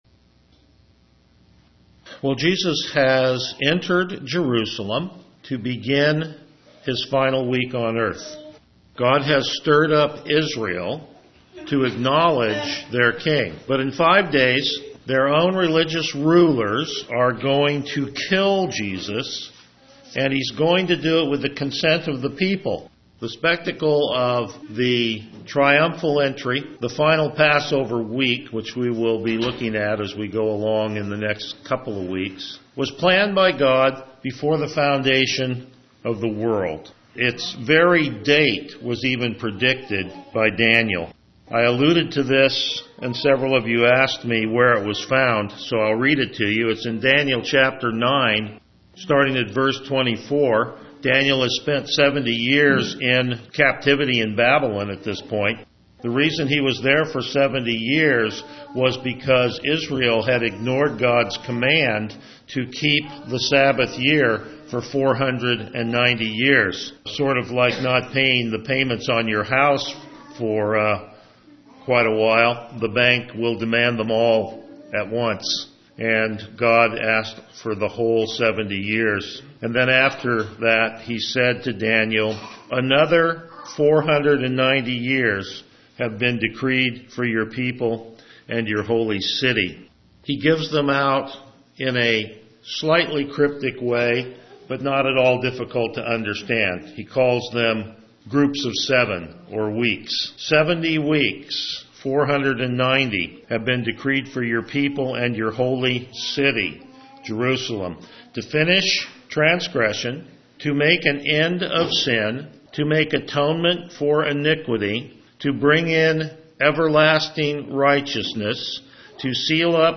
Passage: John 12:20-36 Service Type: Morning Worship